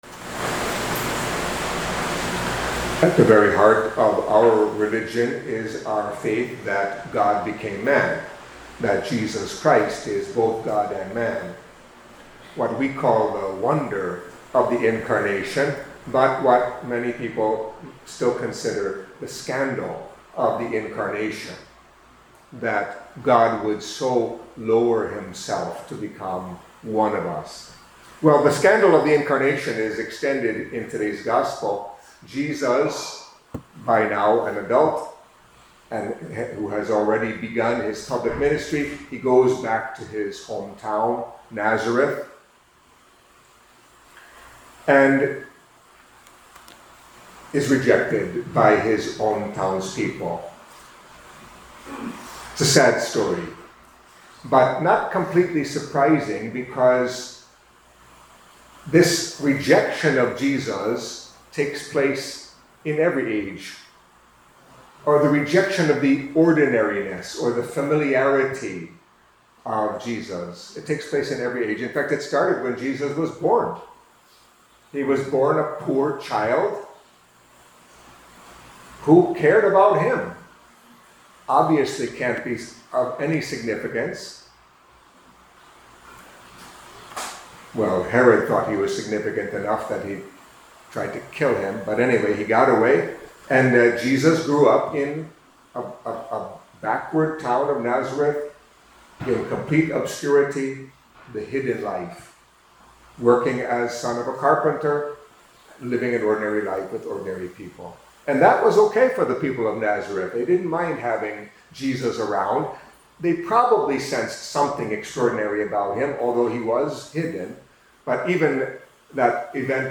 Catholic Mass homily for Friday of the Seventeenth Week in Ordinary Time